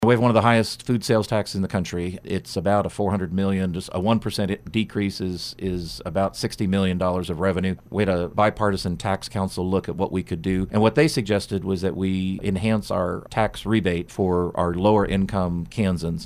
Kansas Lt. Gov. Lynn Rogers stopped by KMAN this morning to discuss various items proposed in Gov. Laura Kelly’s State of the State address and 2020 budget proposal.